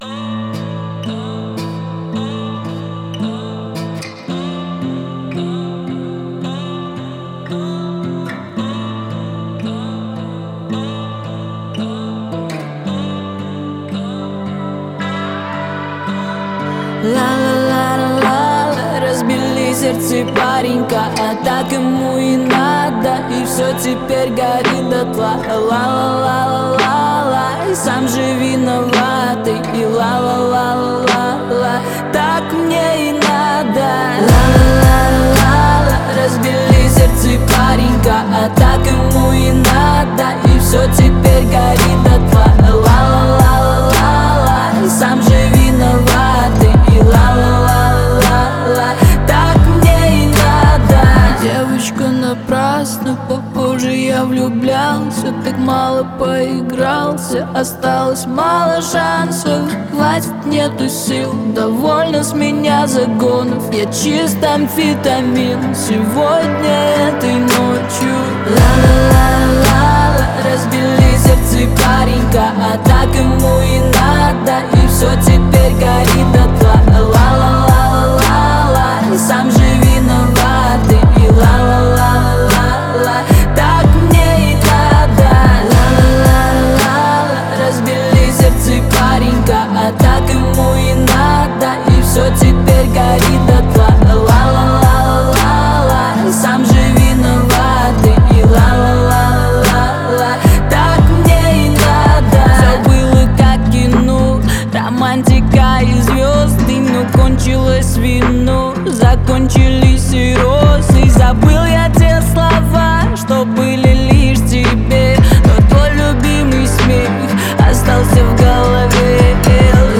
харизматичным вокалом